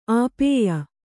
♪ āpēya